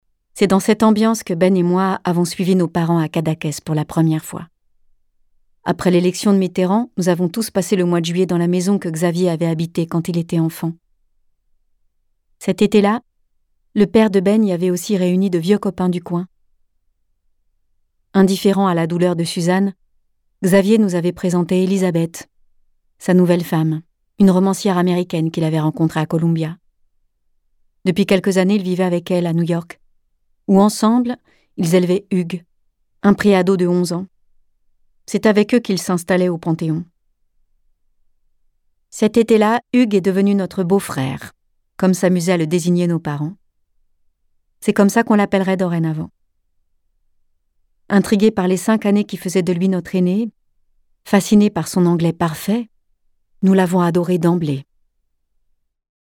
« Immortel » de Camille Kouchner, lu par Isabelle Carré